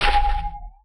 UIClick_Mallet Low Pitch Heavy 01.wav